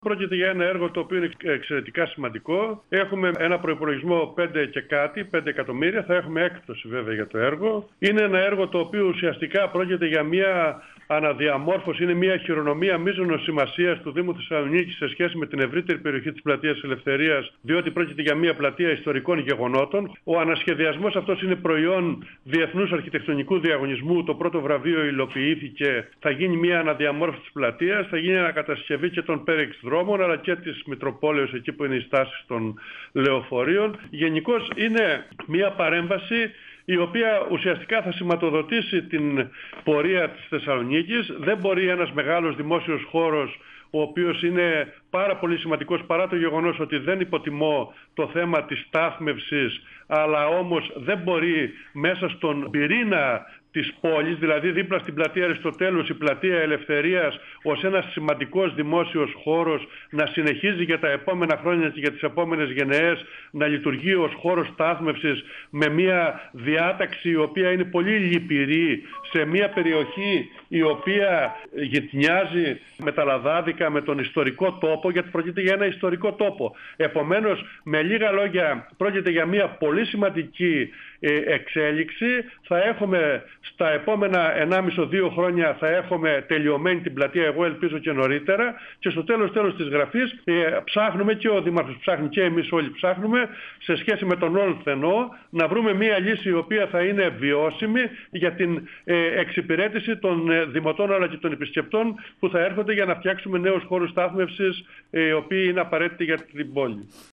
Ο αντιδήμαρχος Τεχνικών Εργων και Περιβάλλοντος, Θανάσης Παππάς,  στον 102FM του Ρ.Σ.Μ. της ΕΡΤ3
Συνέντευξη